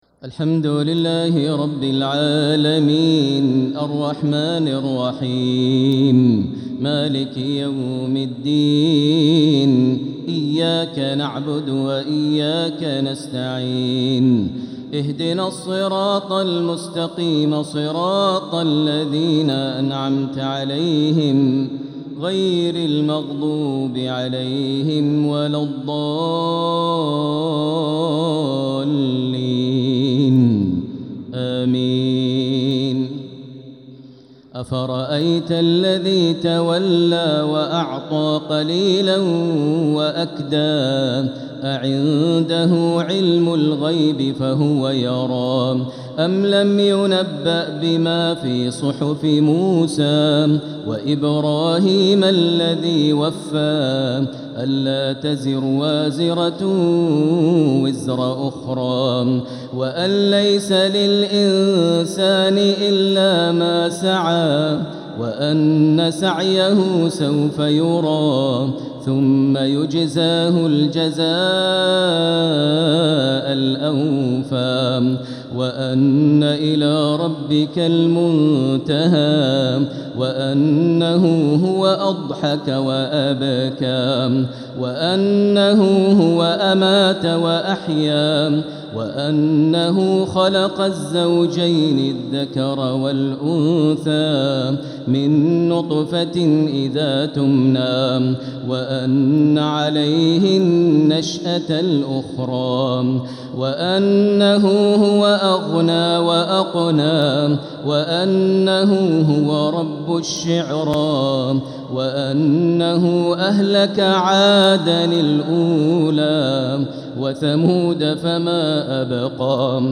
تلاوة من سورة النجم (33-62) الى سورة الرحمن كاملة | تراويح ليلة 27 رمضان 1446هـ > تراويح 1446 هـ > التراويح - تلاوات ماهر المعيقلي